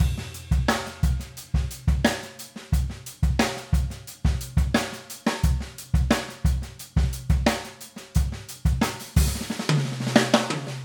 World_Rock